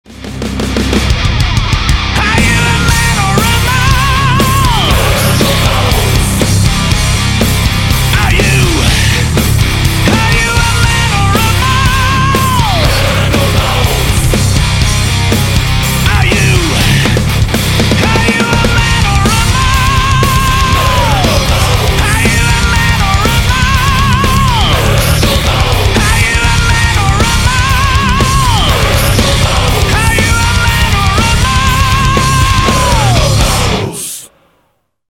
• Качество: 192, Stereo
громкие
heavy Metal
power metal